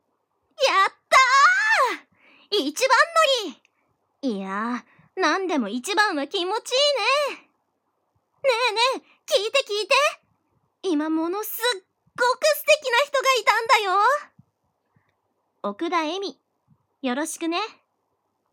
【色彩専門女子校】サンプルボイス